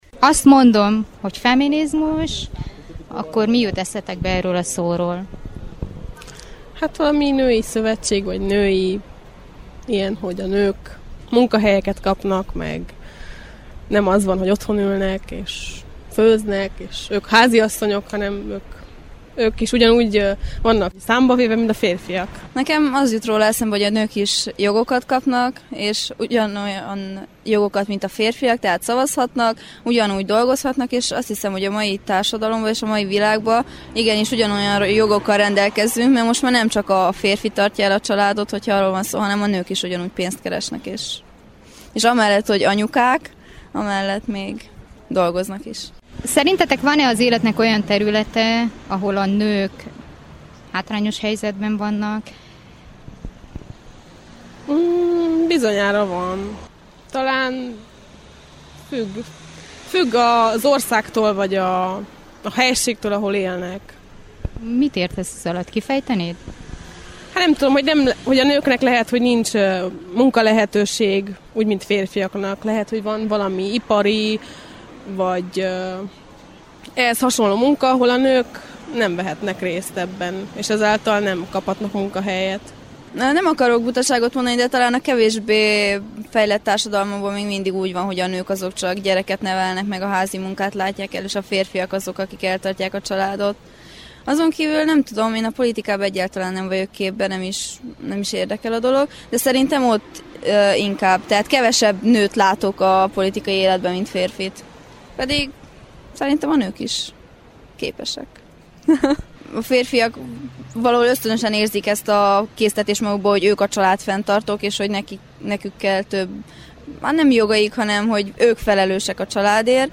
3. Fiatal nők
A fiatal nők válaszai